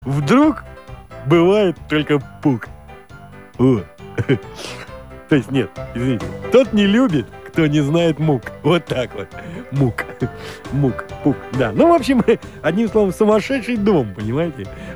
веселые